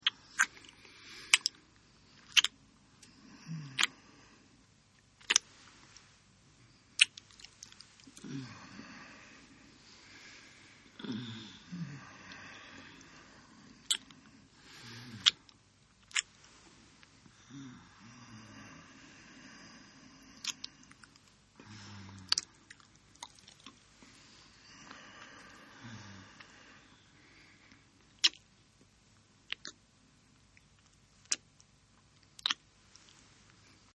Звуки поцелуев
Звук поцелуя парня и девушки